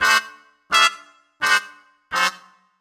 GS_MuteHorn_85-D.wav